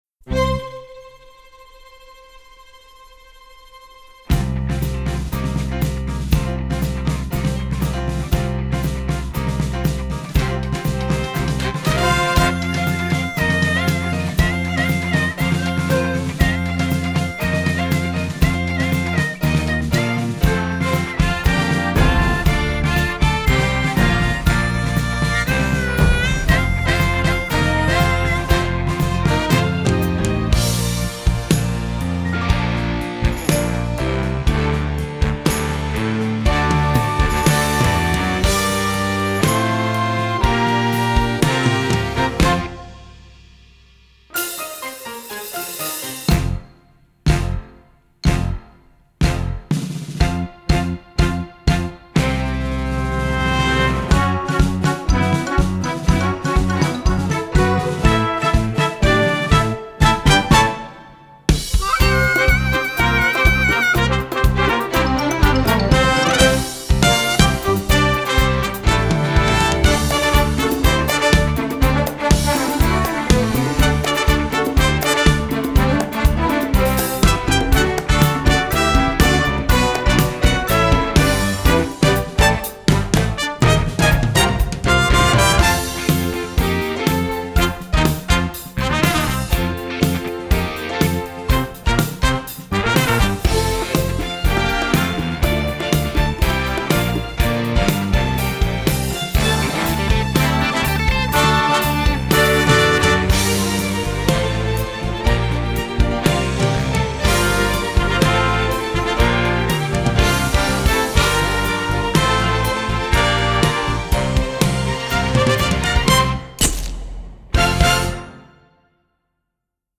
Performance Accompaniment Tracks: